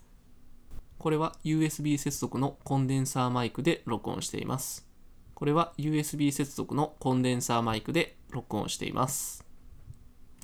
音質テスト
こちらはUSB接続のマイクです。
どちらのマイクも1万円クラスのマイクなのでマイク音質は両方良いですが、FIFINE AmpliGame SC3に接続した方がコンプレッサーなどがなく、自然な感じの音に聞こえます。